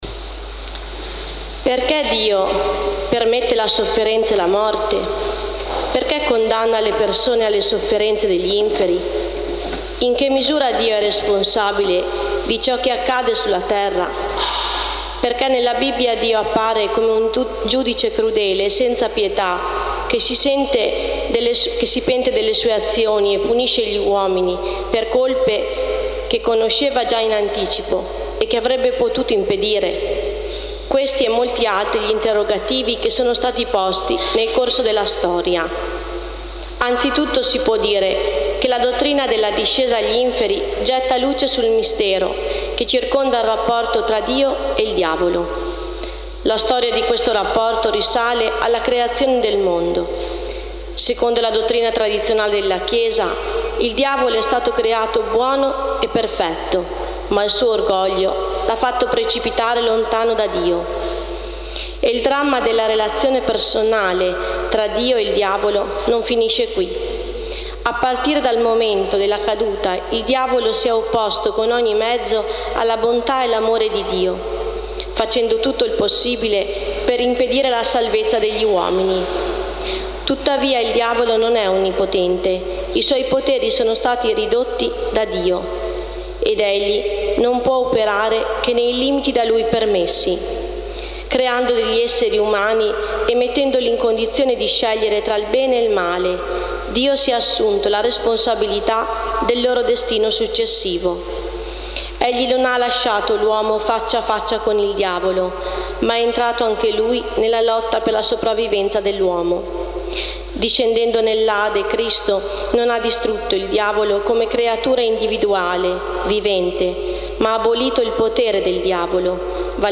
Celebrazione a Roveleto.
Lettura: